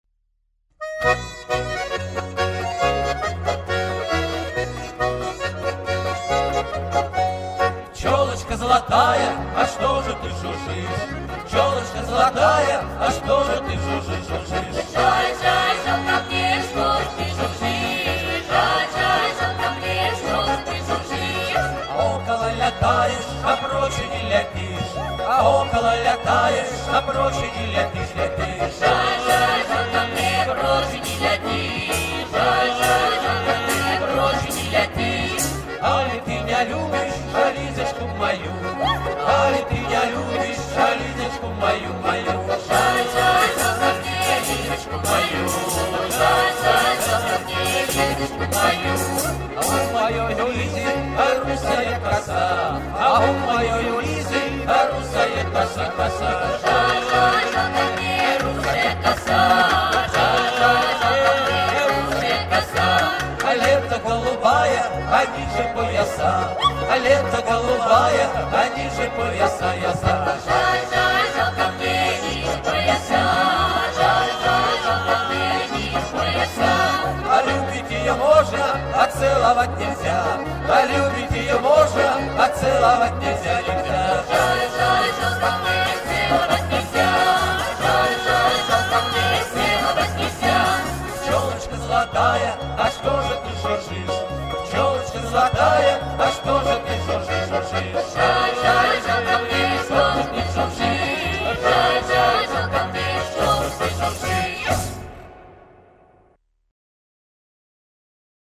теги: фольклорный ансамбль вереск выборг